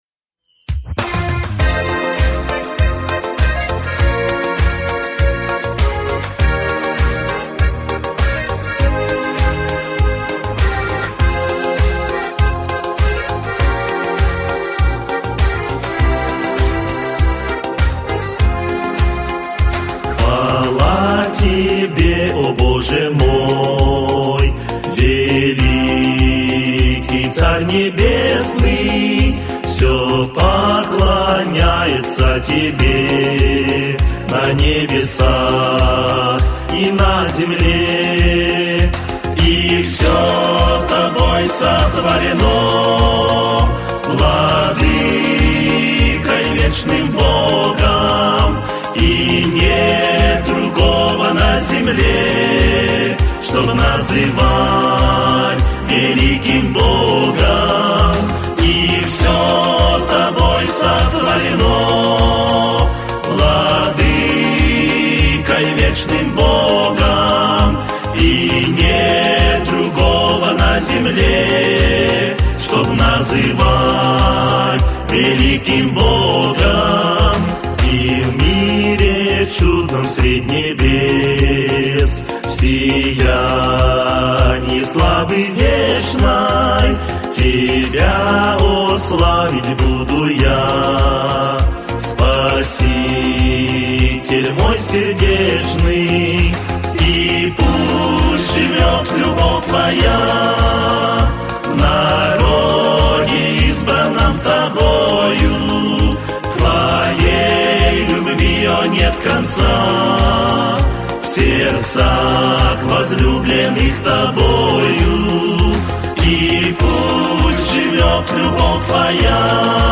319 просмотров 765 прослушиваний 67 скачиваний BPM: 100